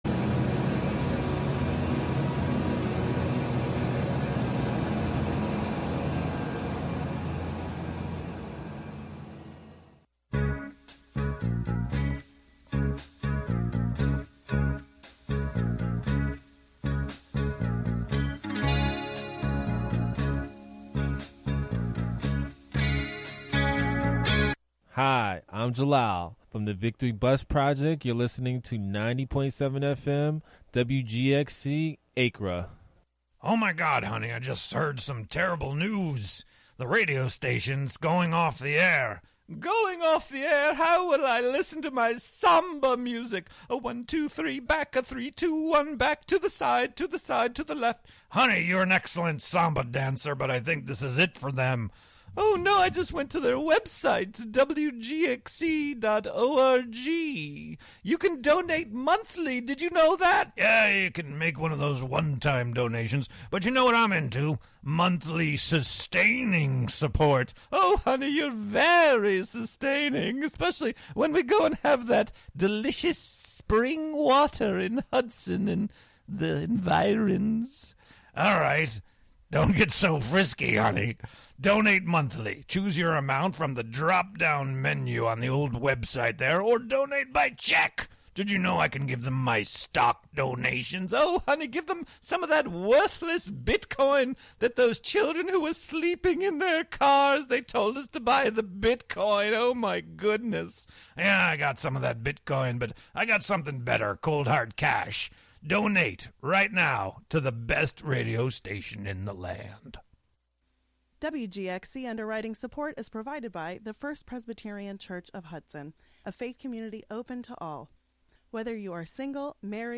On each show, invited guests are asked to discuss a number of items that they would take with them to their Catskill Cabin get-away.